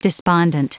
Prononciation du mot despondent en anglais (fichier audio)
Prononciation du mot : despondent
despondent.wav